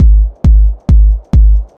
Kick Loops
deep bass tech kick loop.wav
bouncy_reso_techno_kick_loop_poA.wav